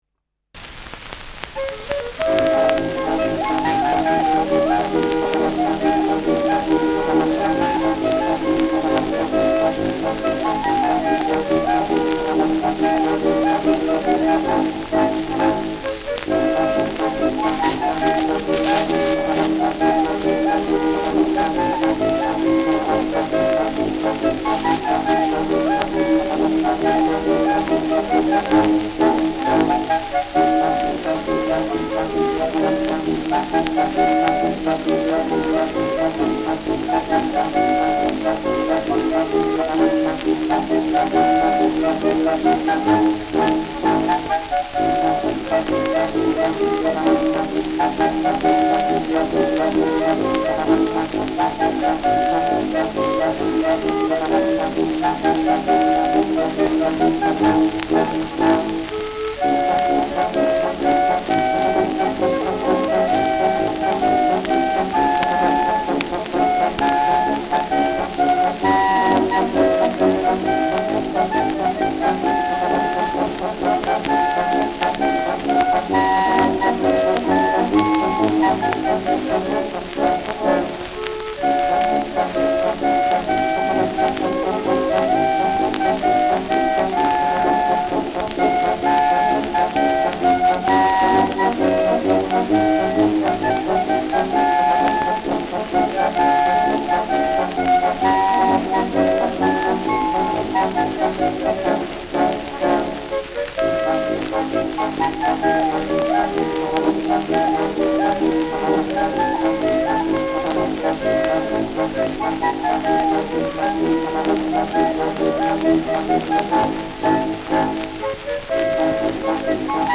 Schellackplatte
präsentes Rauschen : Nadelgeräusch : gelegentliches Knacken : präsentes Knistern
Ländler-Kapelle Urfidele Keferloher (Interpretation)
Das bekannte Volkslied ist hier im Trio zu hören.
Folkloristisches Ensemble* FVS-00015